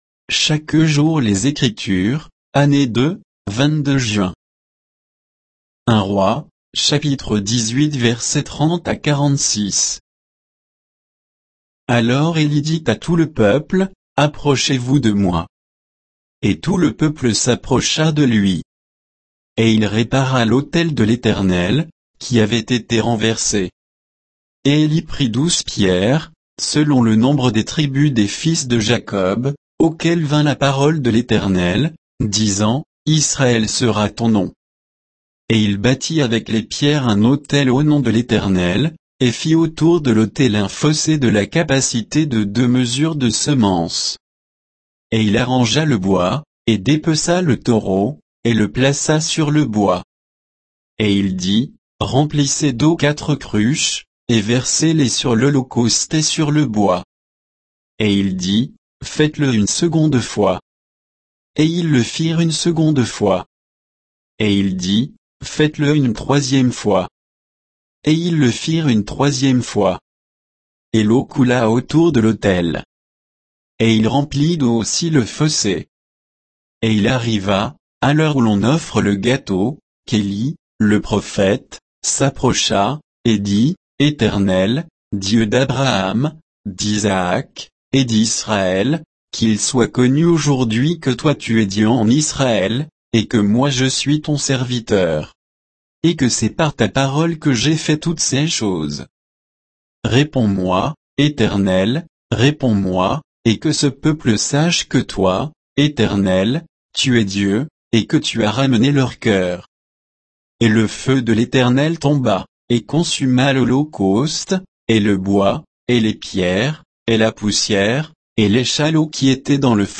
Méditation quoditienne de Chaque jour les Écritures sur 1 Rois 18